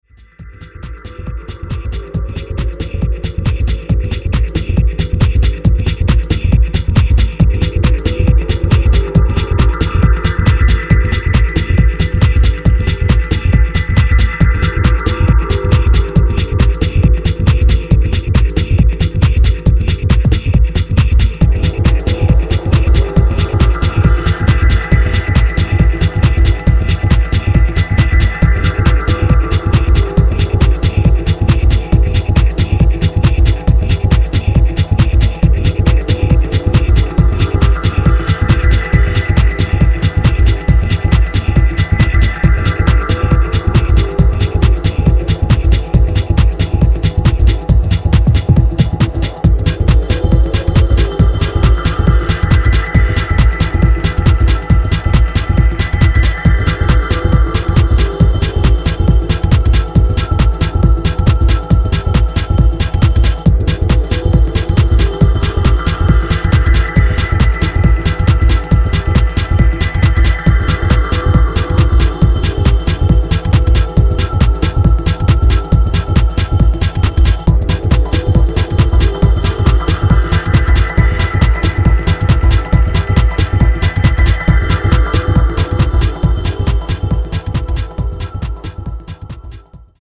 往年の00’s UKテック・ハウス的